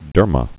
(dûrmə)